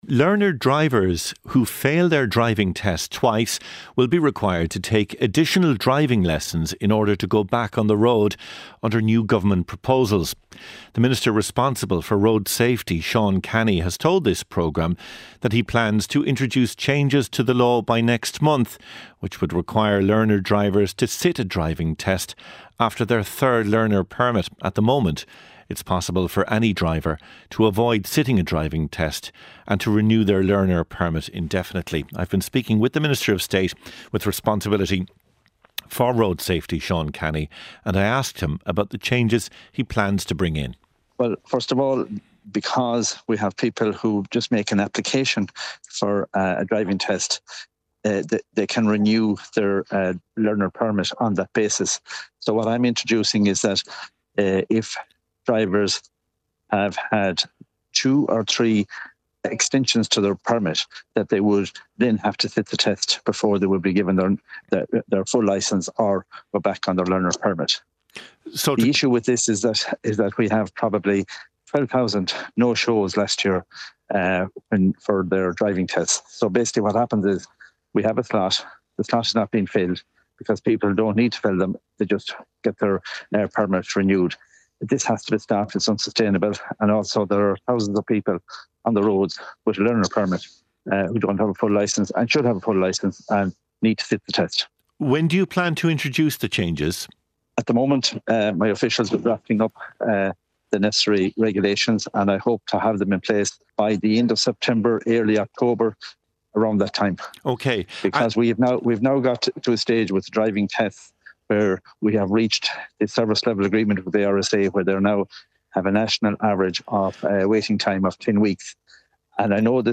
Learner drivers who fail their driving test twice will be required to take additional driving lessons in order to go back on the road under new proposals. Learner drivers will also be required to sit a driving test after their third learner permit. The Minister responsible for road safety, Seán Canney, joined the show to break down the proposals.